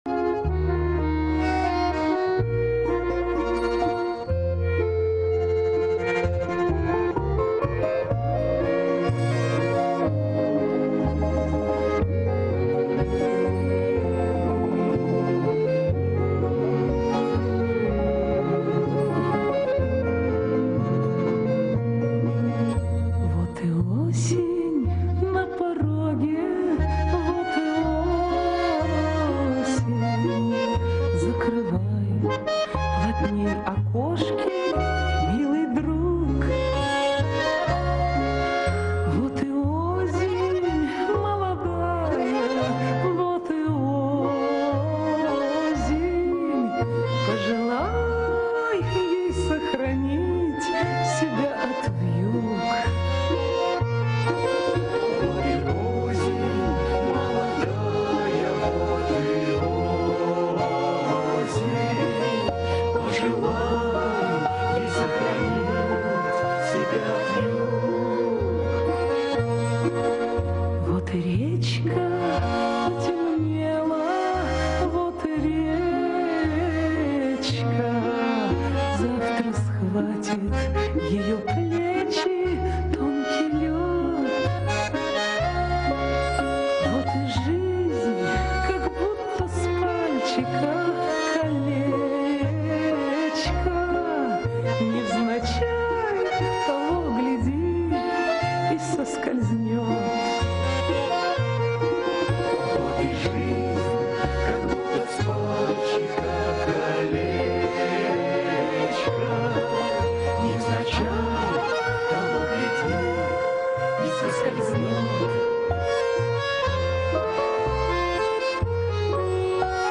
Народный мотив..) Гармонь...